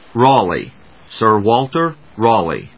/rˈɔːli(米国英語), ˈrɔ:li:(英国英語)/